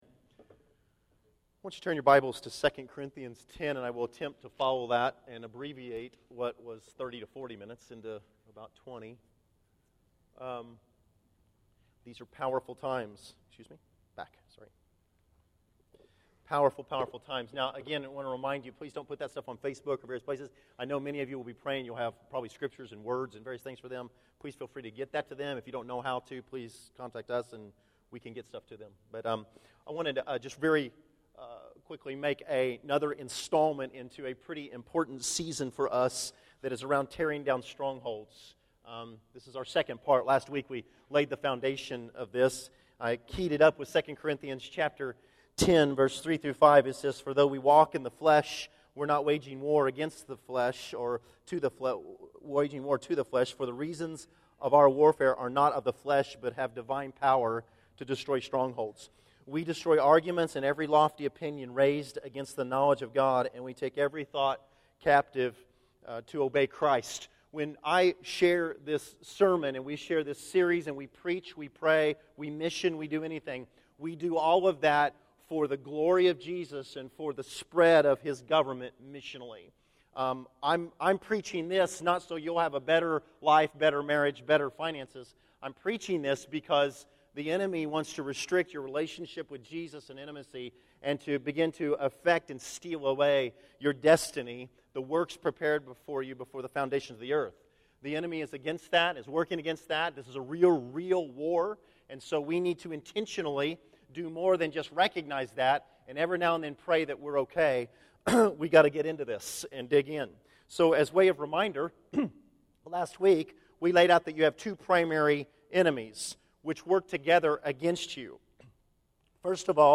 Tearing Down Strongholds: Part 2 June 02, 2013 Category: Sermons | Back to the Resource Library Video Audio Part 2 of a three-part series about the battle against strongholds.